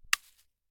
46265b6fcc Divergent / mods / Bullet Shell Sounds / gamedata / sounds / bullet_shells / generic_leaves_5.ogg 15 KiB (Stored with Git LFS) Raw History
generic_leaves_5.ogg